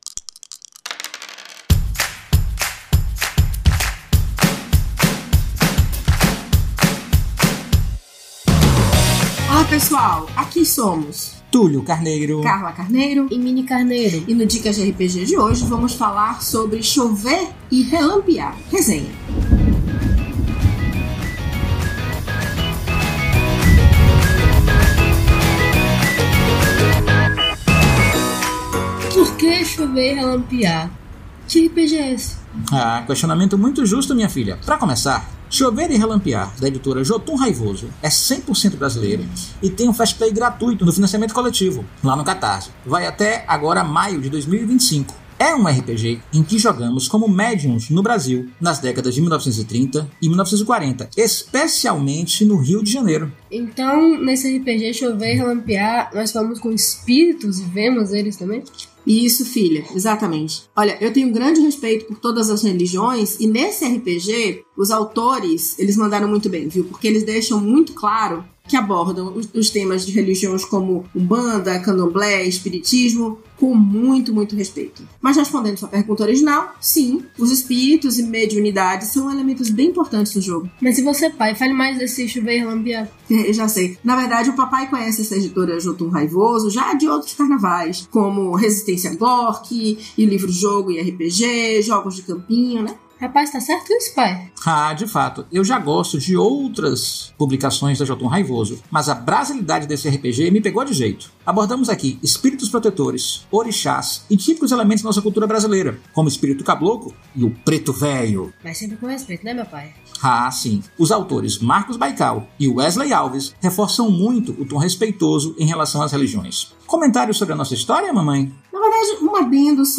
O Dicas de RPG é um podcast semanal no formato de pílula que todo domingo vai chegar no seu feed.